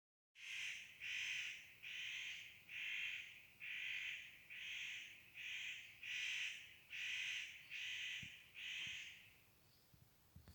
Birds -> Crows ->
Nutcracker, Nucifraga caryocatactes
StatusSpecies observed in breeding season in possible nesting habitat